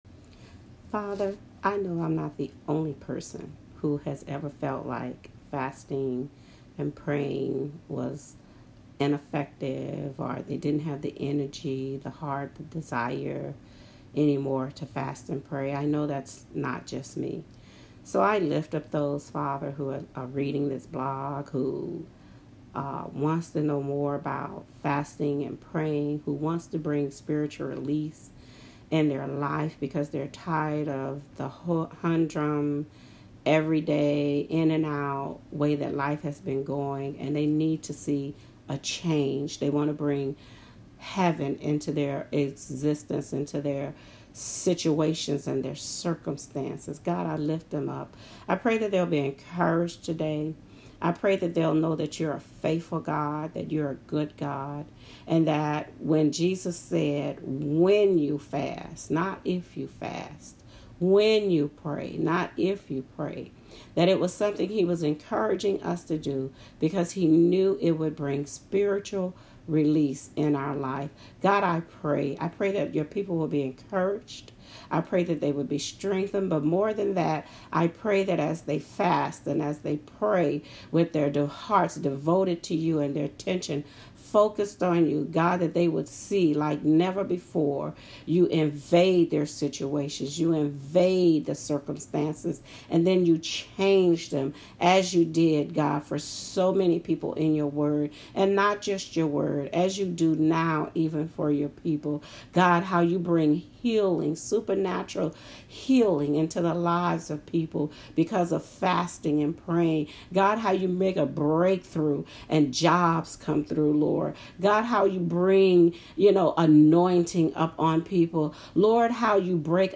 prayer-for-spiritual-release.m4a